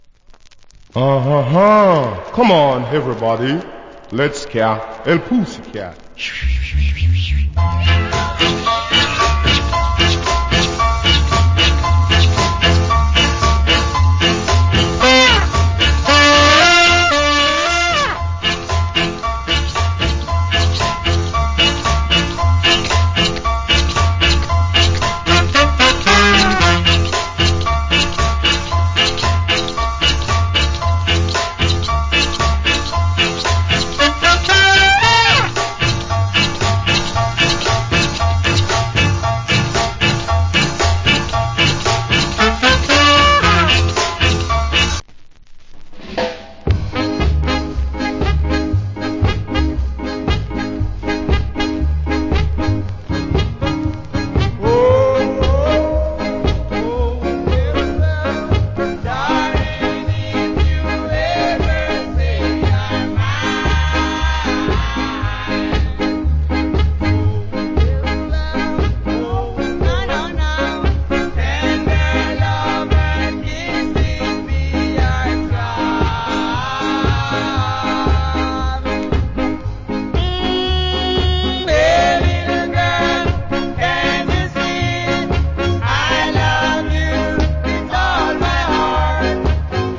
Nice Ska Inst.